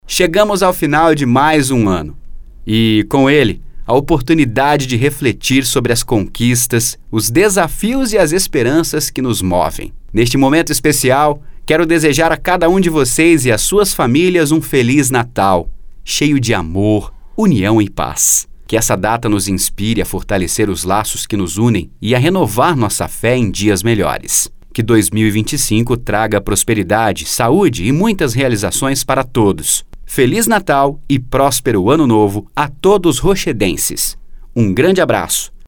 OFF MENSAGEM DE NATAL 2024:
Padrão
Animada